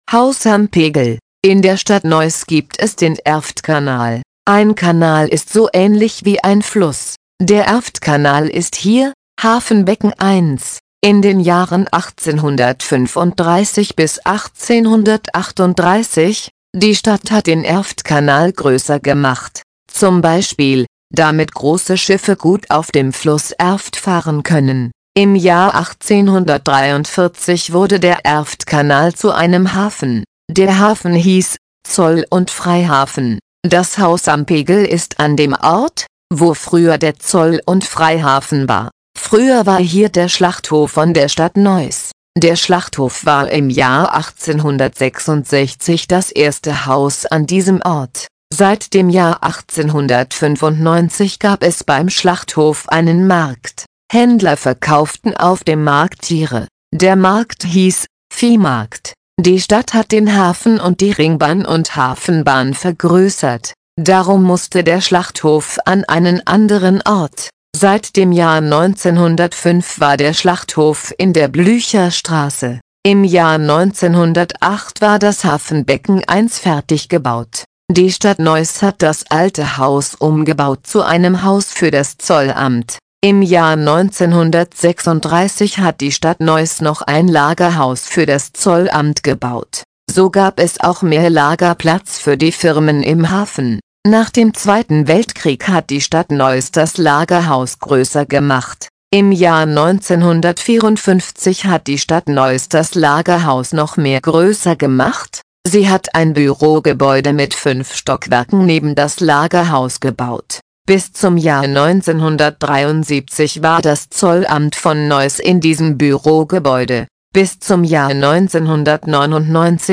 Audio Guide Deutsch (Leichte Sprache)